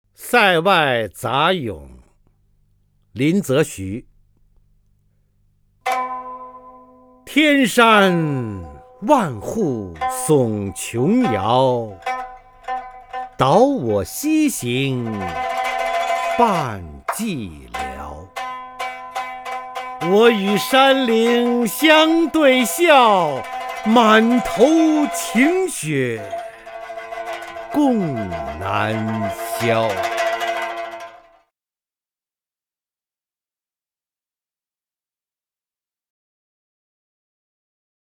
方明朗诵：《塞外杂咏》(（清）林则徐) （清）林则徐 名家朗诵欣赏方明 语文PLUS
（清）林则徐 文选 （清）林则徐： 方明朗诵：《塞外杂咏》(（清）林则徐) / 名家朗诵欣赏 方明